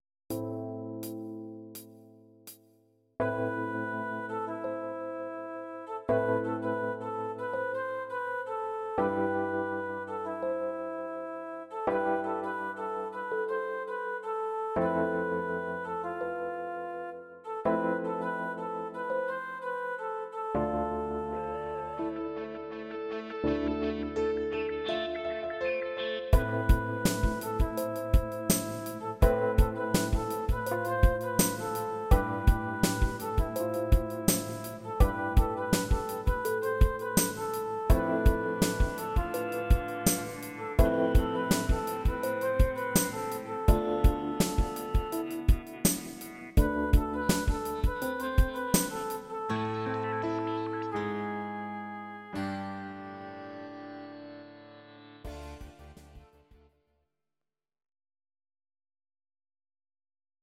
These are MP3 versions of our MIDI file catalogue.
Your-Mix: Rock (2958)